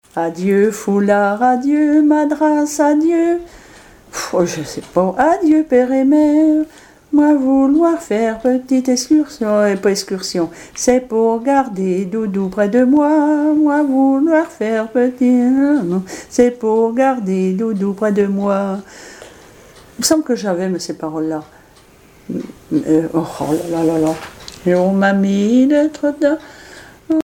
Chansons et commentaires
Catégorie Pièce musicale inédite